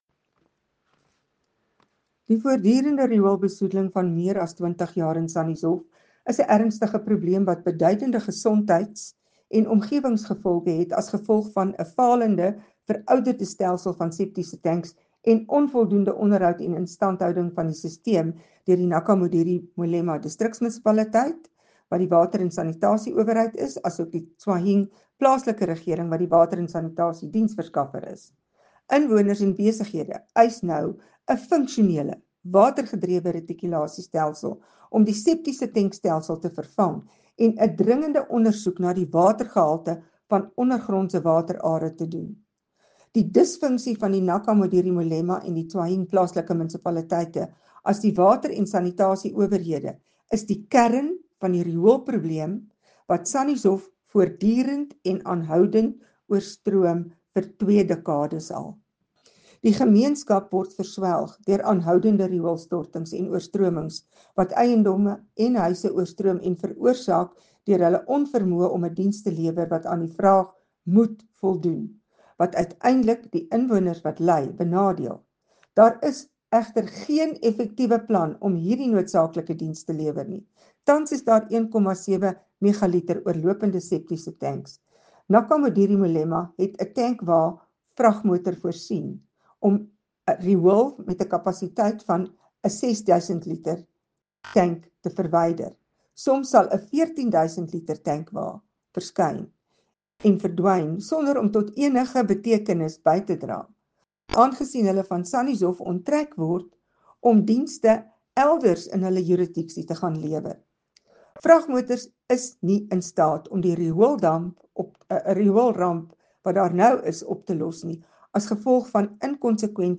Issued by Carin Visser – DA Councillor, Tswaing Local Municipality
Note to Broadcasters: Please find attached soundbites in